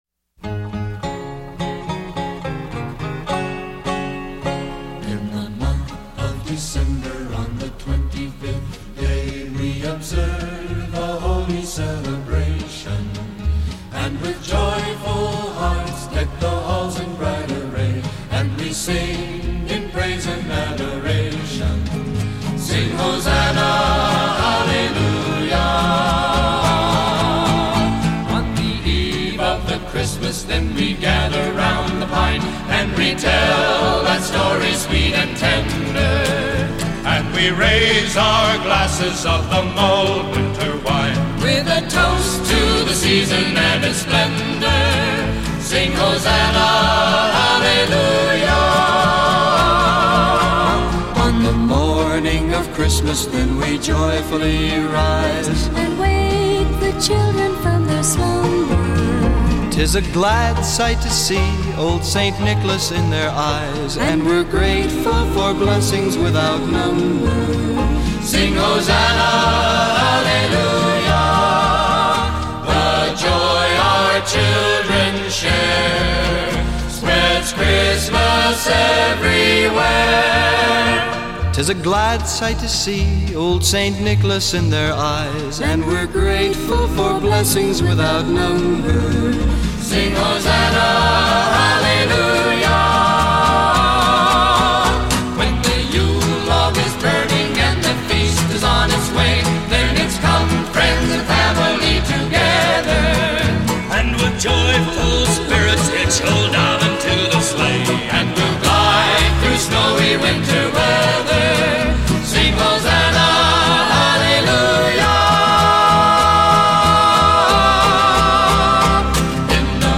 rousing, full-throated ensemble pieces